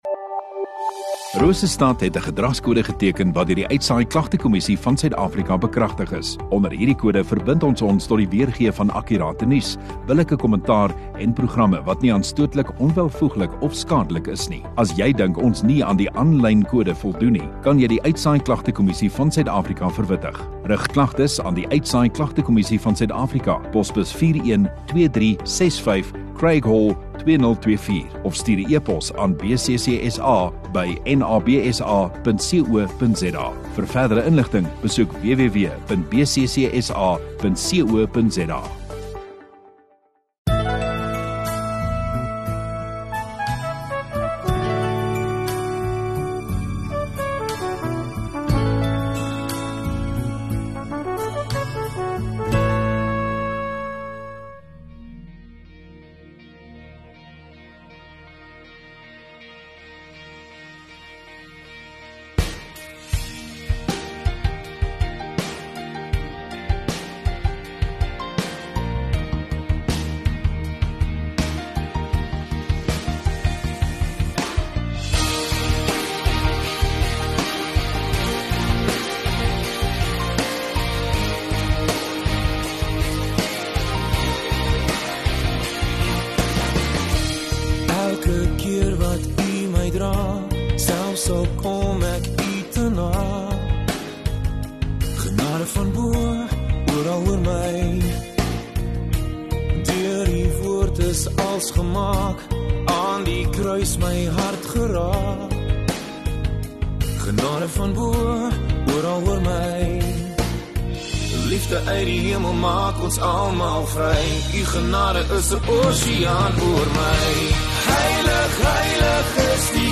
28 Feb Saterdag oggenddiens